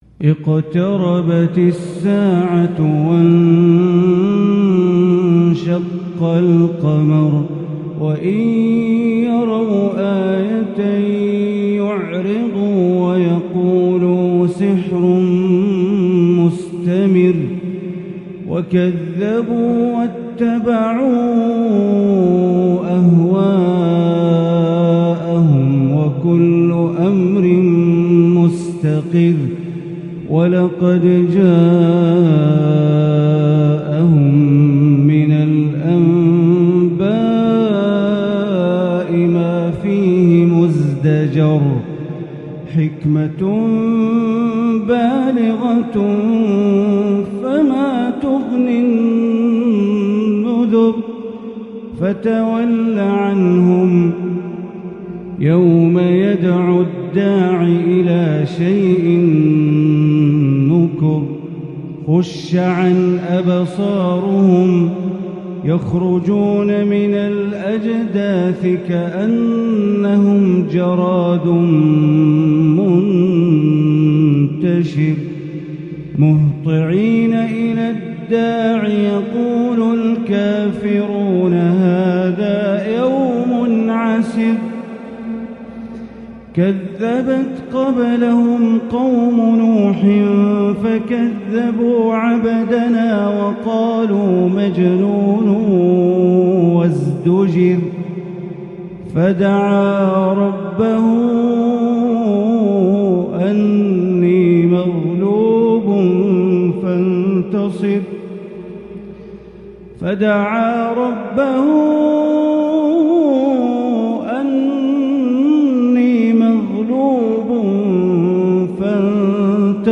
سورة القمر > مصحف الحرم المكي > المصحف - تلاوات بندر بليلة